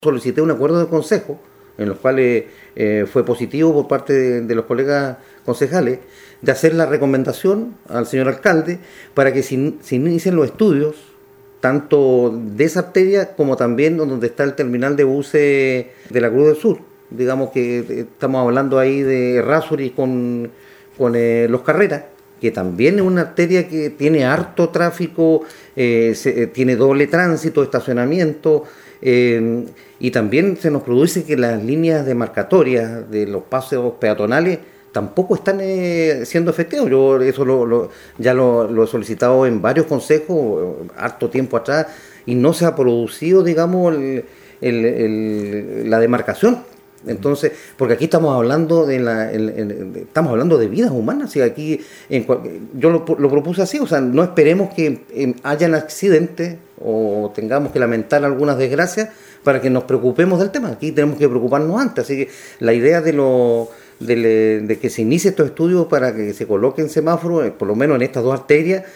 Otro de los puntos de flujo mayor de vehículos es el de Errázuriz y Los Carrera, que tiene los mismos problemas que la anterior intersección, señaló el concejal Marcos Velásquez.
11-CONCEJAL-MARCOS-VELASQUEZ-2.mp3